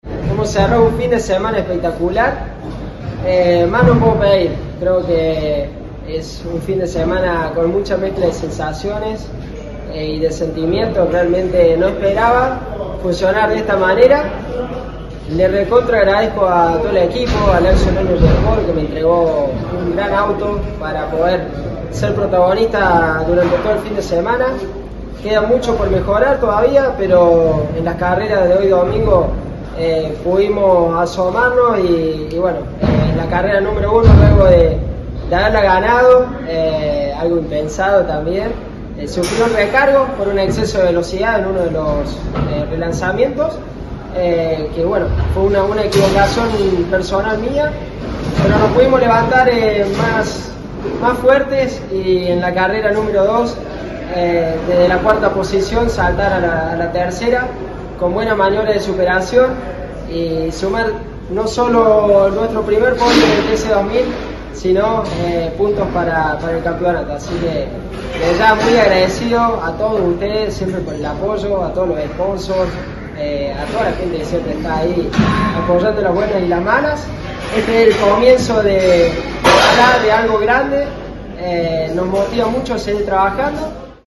al finalizar la competencia